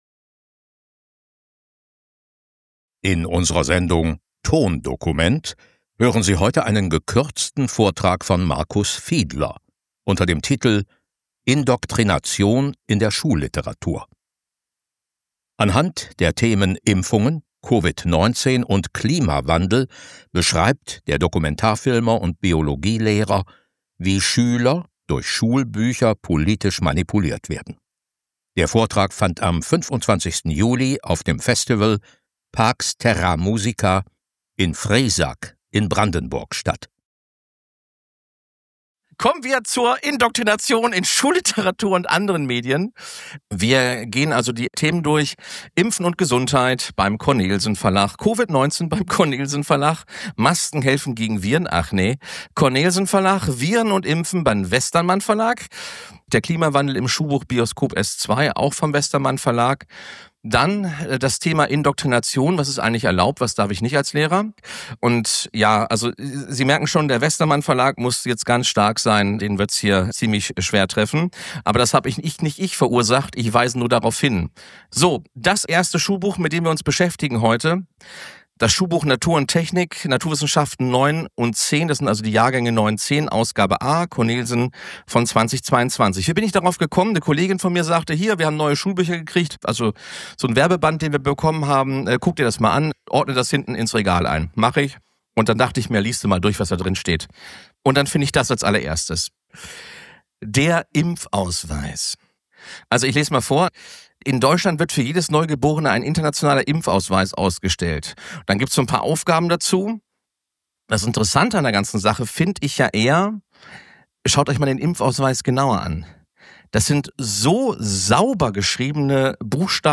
Eingangsstatement
vor dem Corona-Untersuchungsausschuss Sachsen